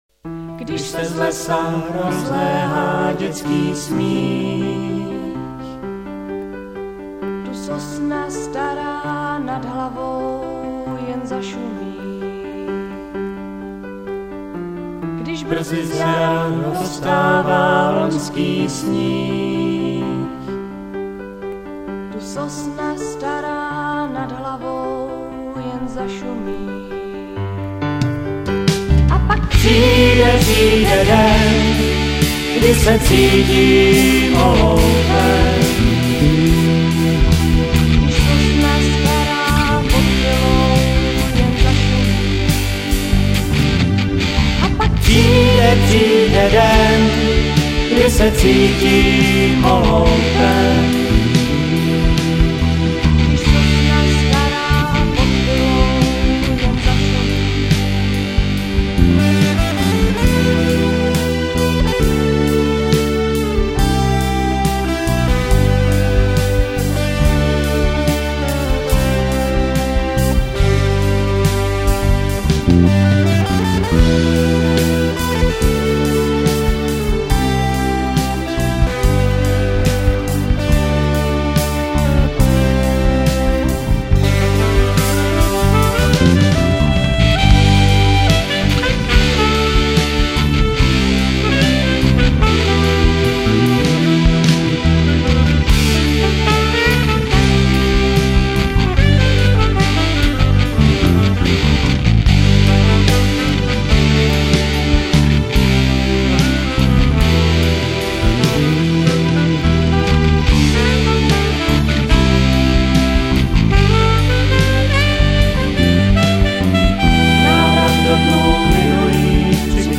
(Běta a Karel zpívají píseň.)